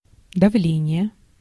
Ääntäminen
US : IPA : [ˈpɹɛʃ.ɚ]